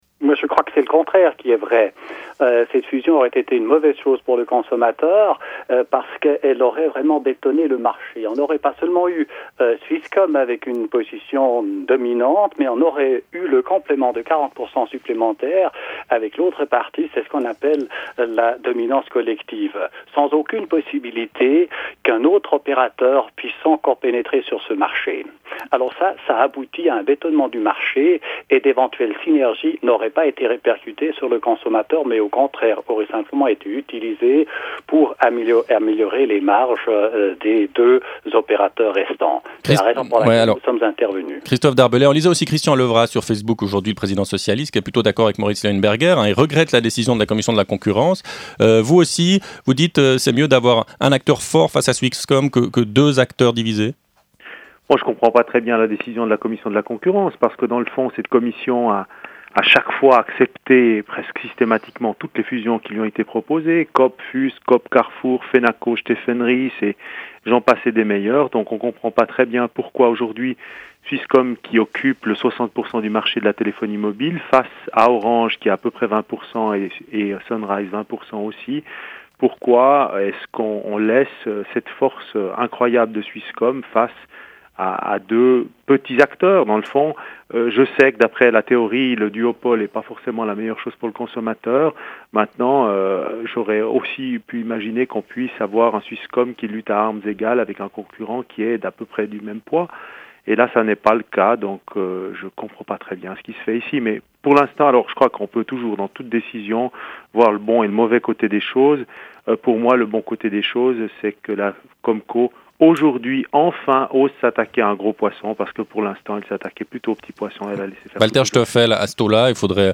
Débat (RSR) Un nouveau géant n’aurait-il pas eu plus de poids face à Swisscom ?
Walter Stoffel, président de la Commission de la concurrence et Christophe Darbellay, président du parti démocrate-chrétien